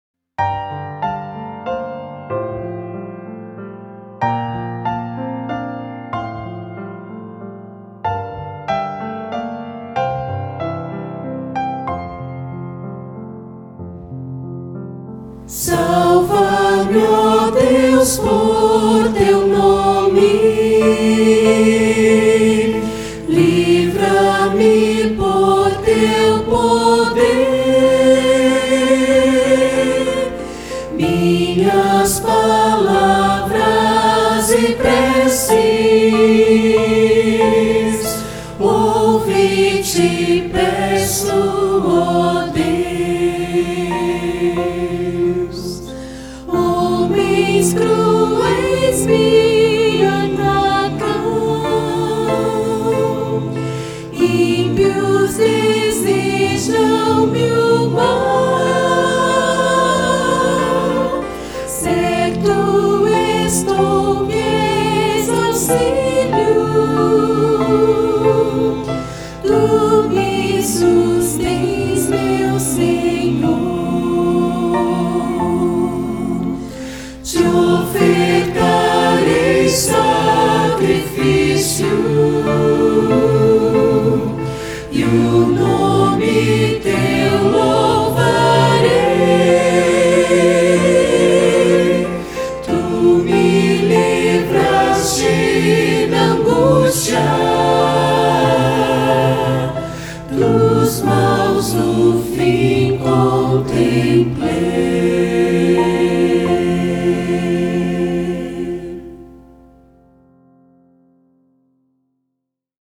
Kit de ensaio para Coro completo